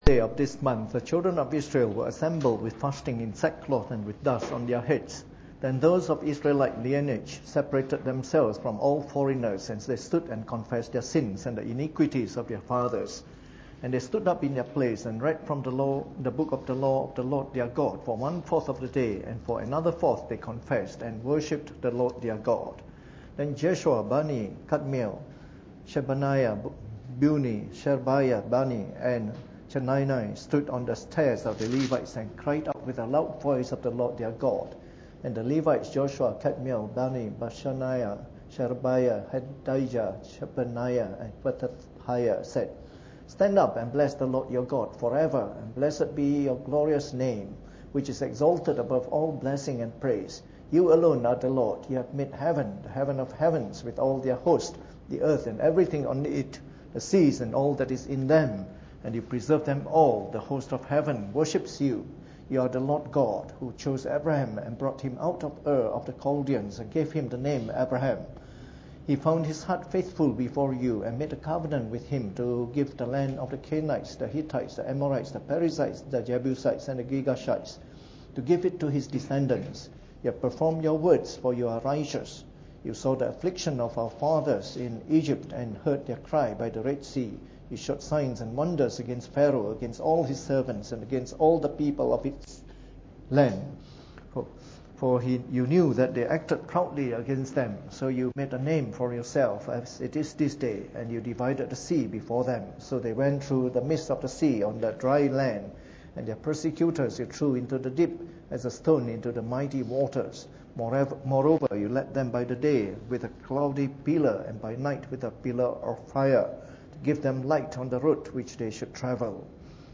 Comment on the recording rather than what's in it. Preached on the 25th of June 2014 during the Bible Study, from our series of talks on the Book of Nehemiah.